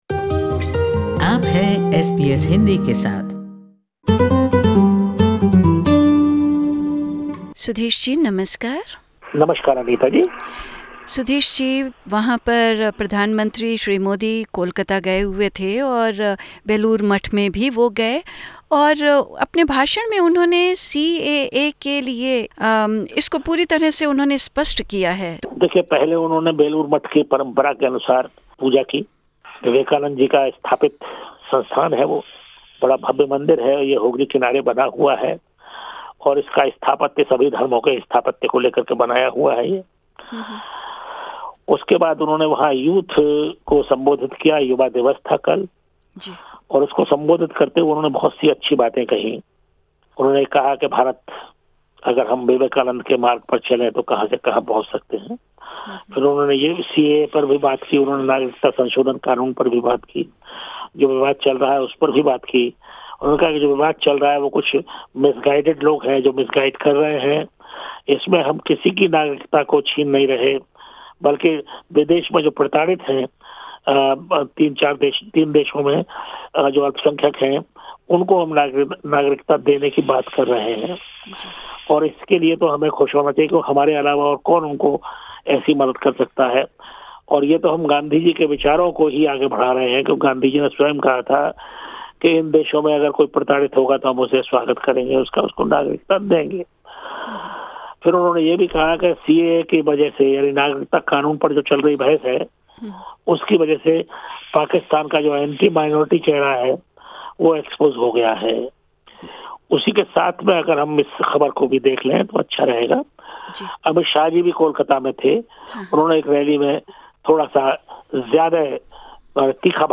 gives us a report on this and other news stories.